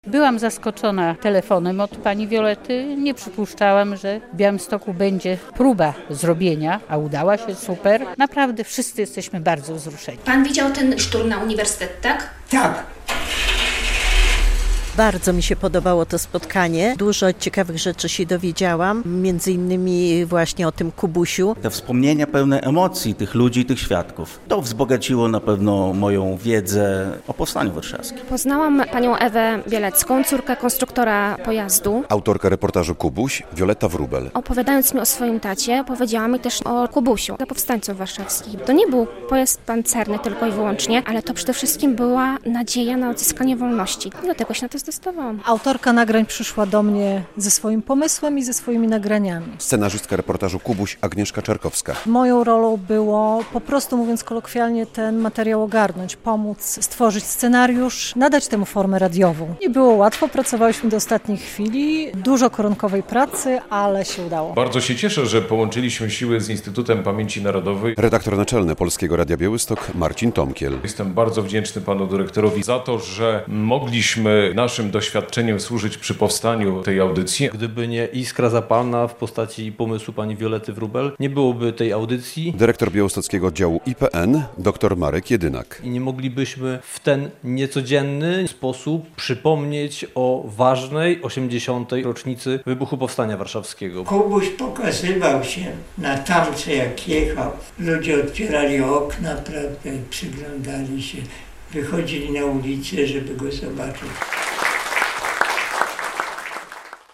W środę (31.07) kilkadziesiąt osób miało okazję przedpremierowo wysłuchać w Studiu Rembrandt Polskiego Radia Białystok reportaż "Kubuś".